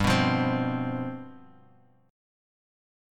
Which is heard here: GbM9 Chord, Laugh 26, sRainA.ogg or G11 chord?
G11 chord